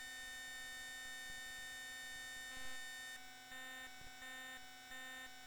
My unit makes a digital noise that sounds like a hardware fault.